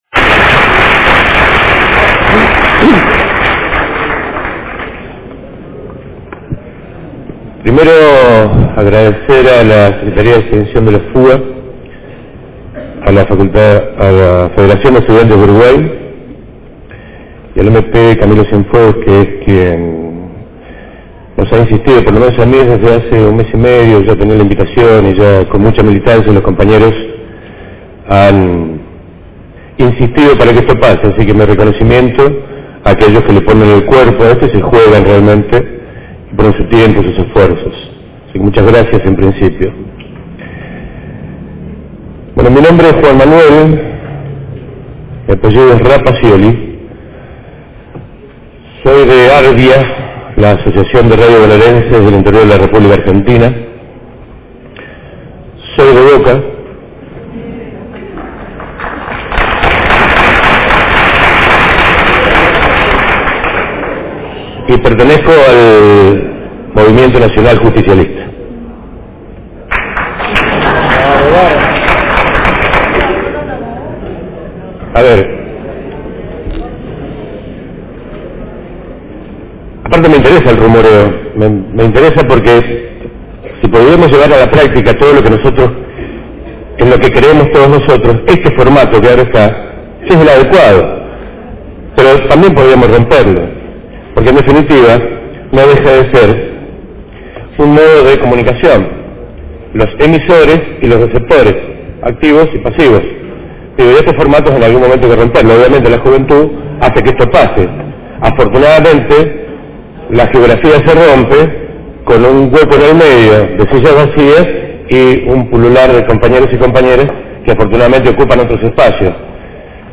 Como radio de la Universidad de la República, cubrimos el evento desde el lugar de los hechos